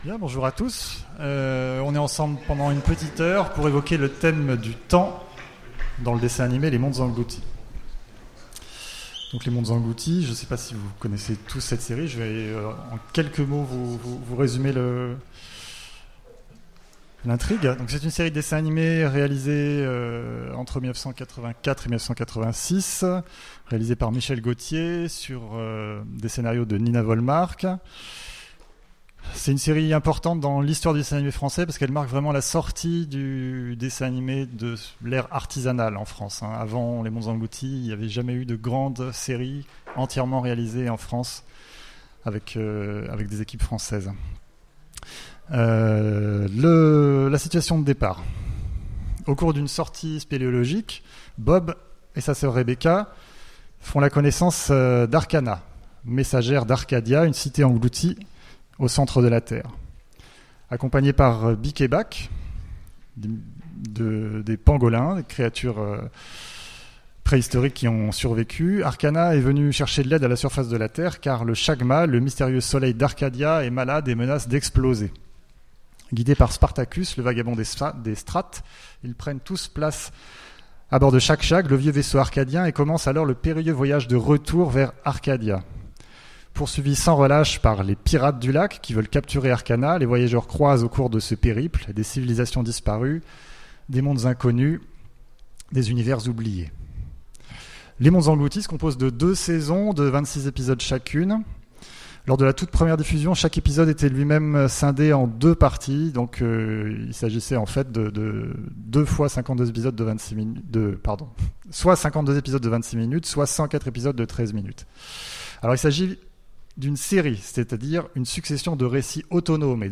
Mots-clés Cinéma Conférence Partager cet article